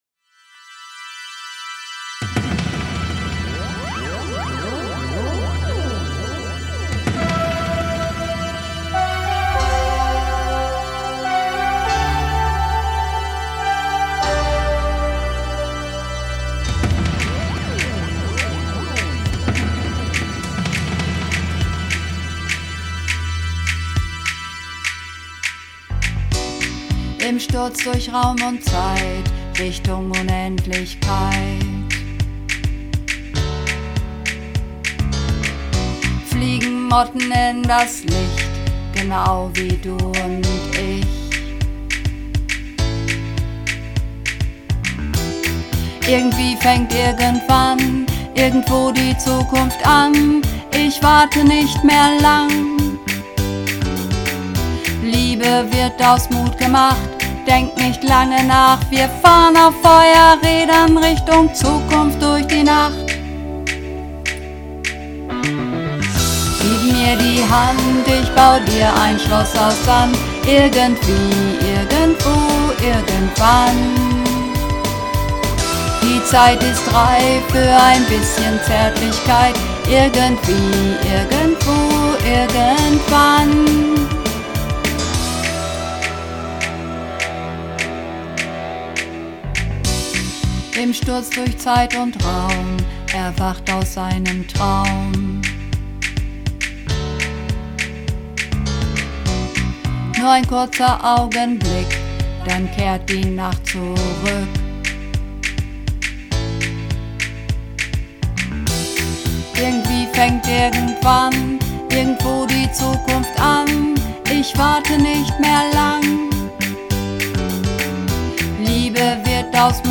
Übungsaufnahmen - Irgendwie, irgendwo, irgendwann
Irgendwie_irgendwo_irgendwann__2_Bass.mp3